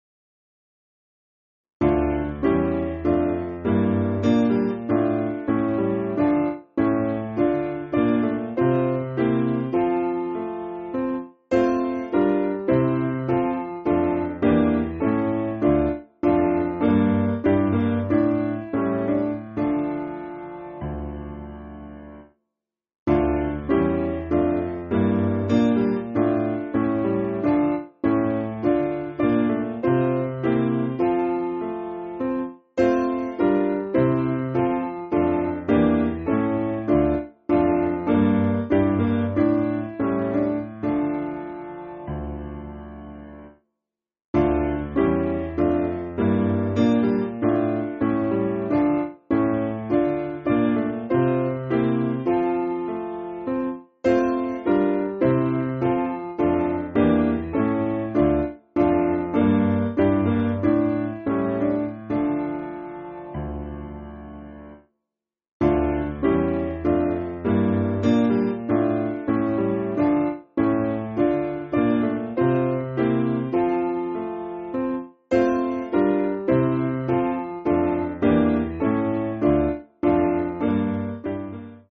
Simple Piano
(CM)   5/Dm